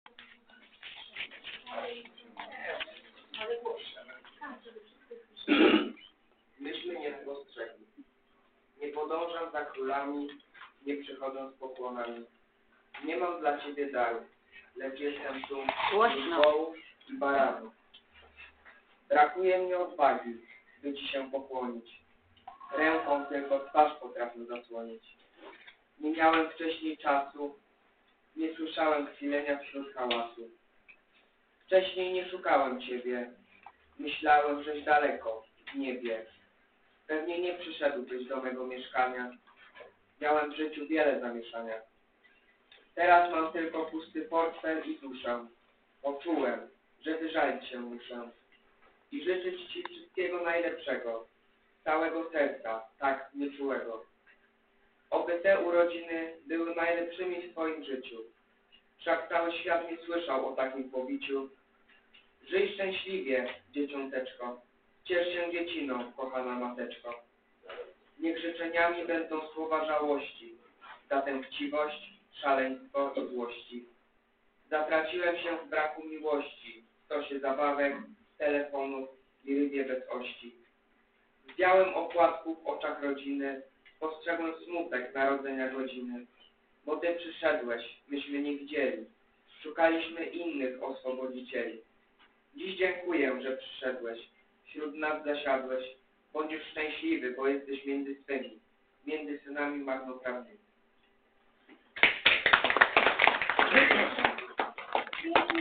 Oto wybrane fragmenty spotkania (przepraszamy za usterki w nagraniach) oraz teksty kolęd do ew. pobrania i foto-galeria.